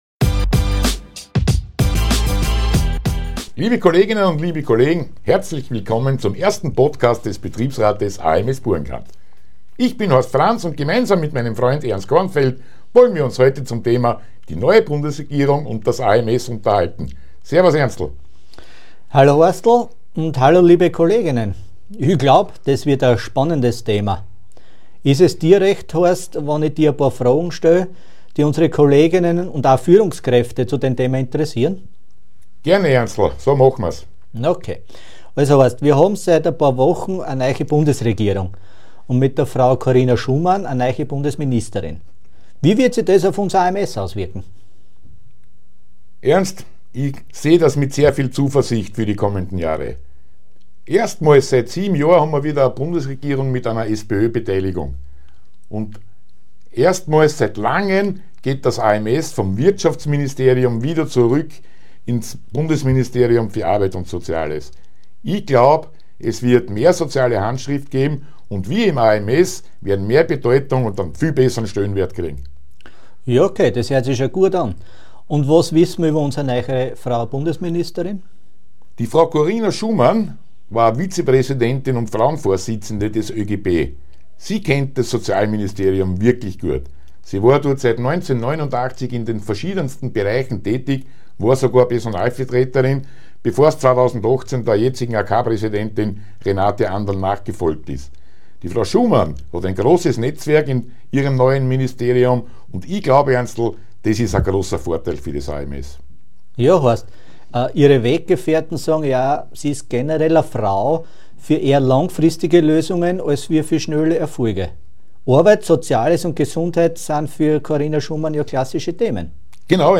führen dieses Gespräch.